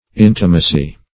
intimacy - definition of intimacy - synonyms, pronunciation, spelling from Free Dictionary
Intimacy \In"ti*ma*cy\, n.; pl. Intimacies. [From Intimate.]